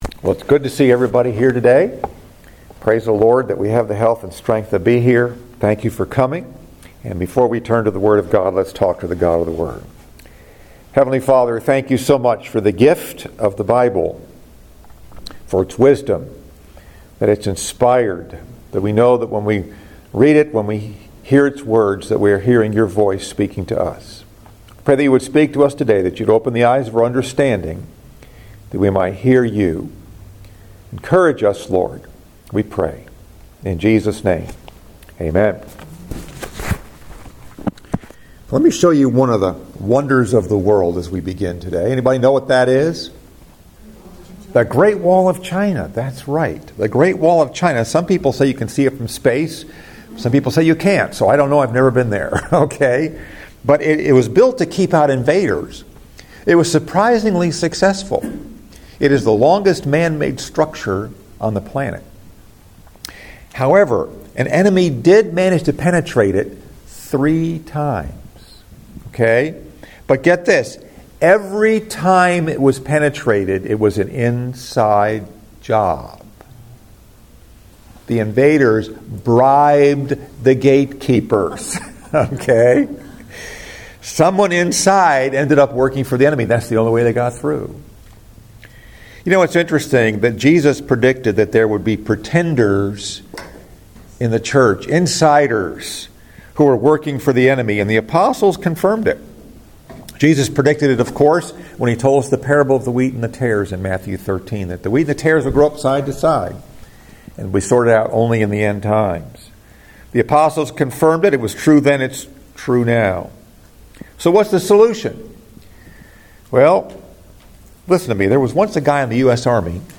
Message: “Apostasy Survival Guide” Scripture: Jude verses 17-19